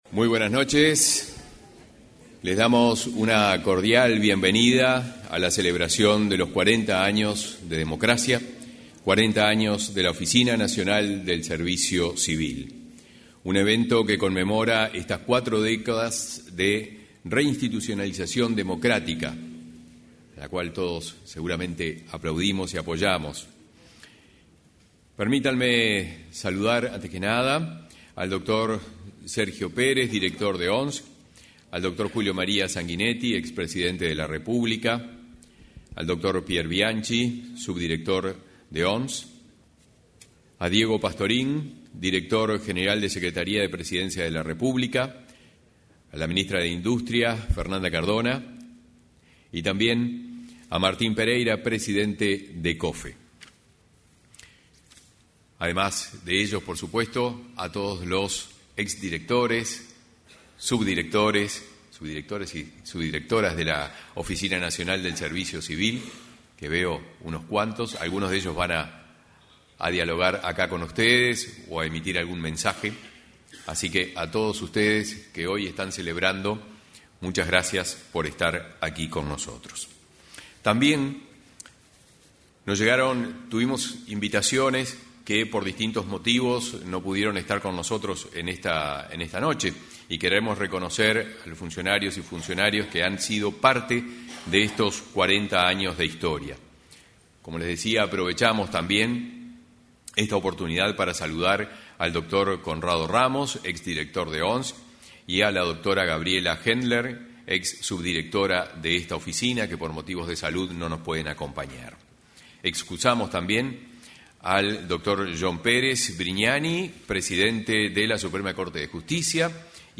Durante la ceremonia por el 40.° aniversario de la Oficina Nacional del Servicio Civil (ONSC) en democracia, realizada en la Torre Ejecutiva,